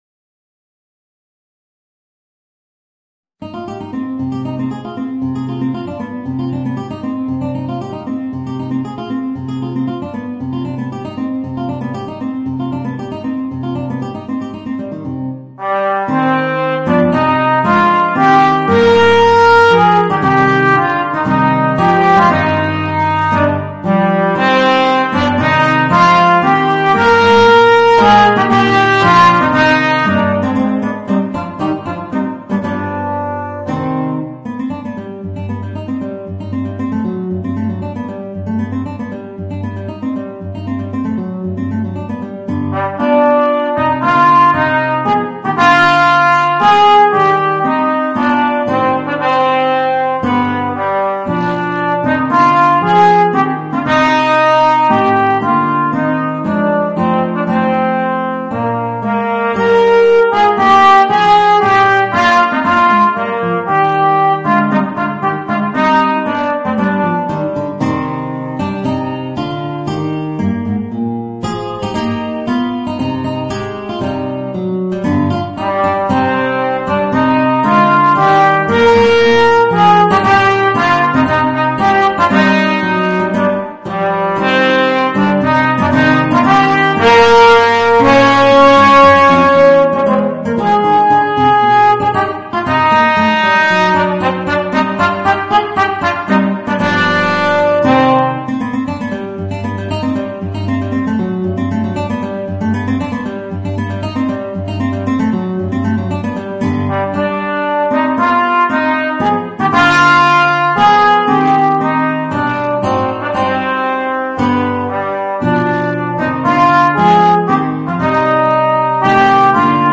Voicing: Alto Trombone and Guitar